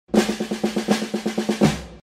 Drum